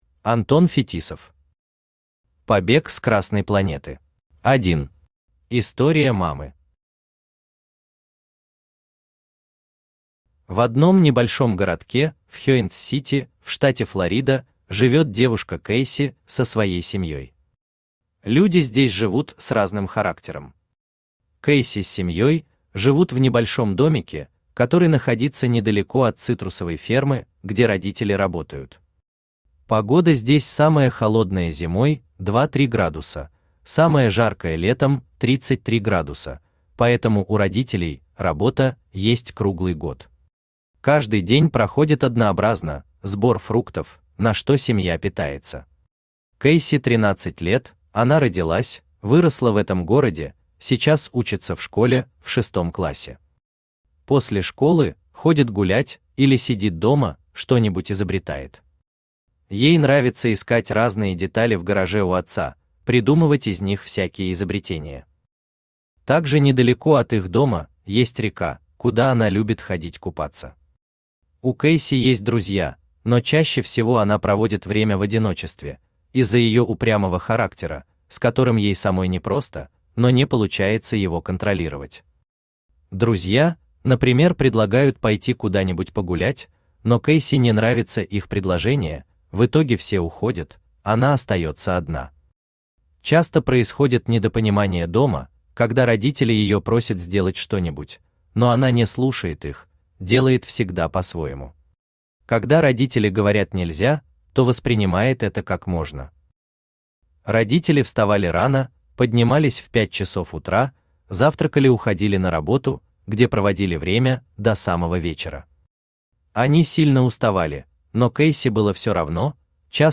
Aудиокнига Побег с красной планеты Автор Антон Евгеньевич Фетисов Читает аудиокнигу Авточтец ЛитРес.